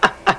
Cackle Sound Effect
Download a high-quality cackle sound effect.
cackle.mp3